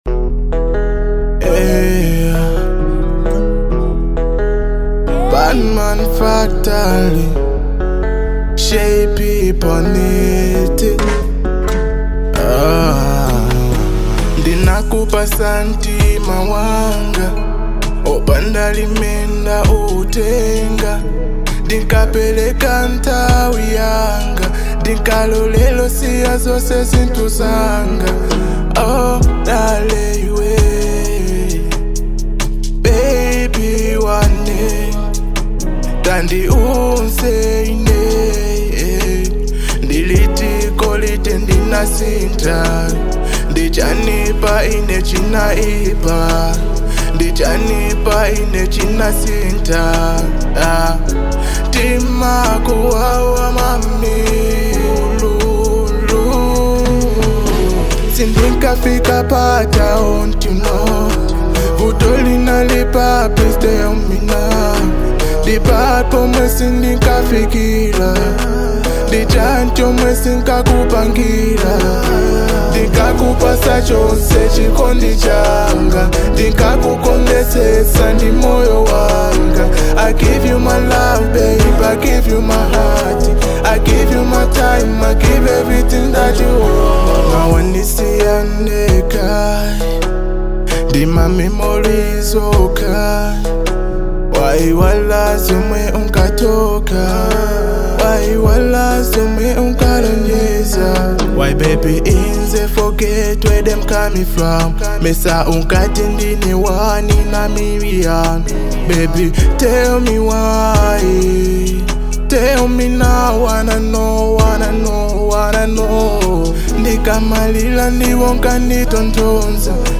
Afro-Beat